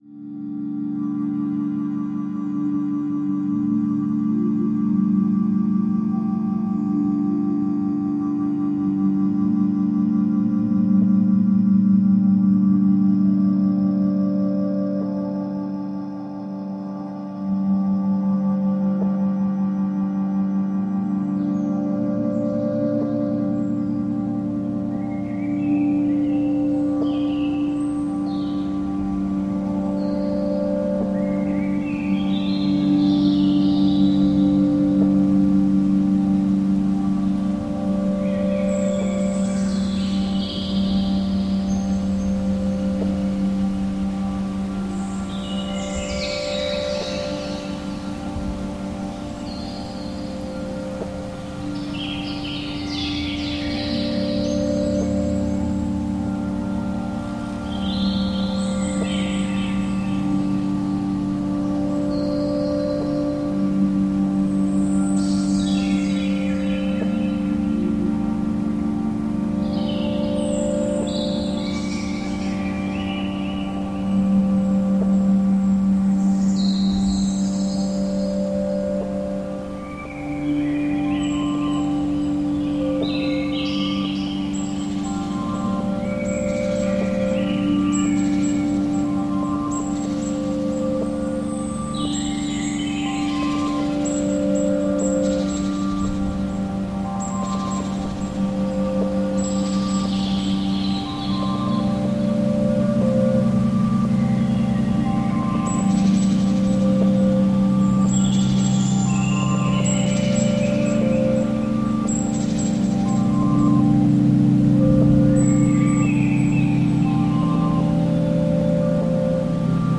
birdsong merges with synths and manipulated glass sounds.